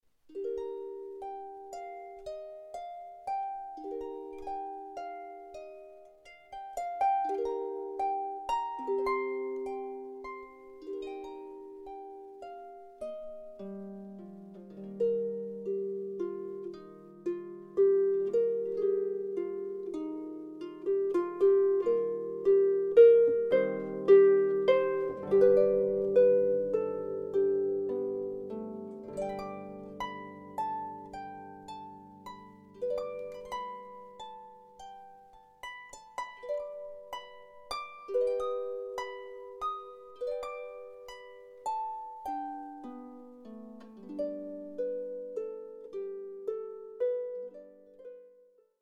An award-winning solo harpist who specialises in classical & contemporary music.